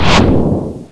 bazooka.wav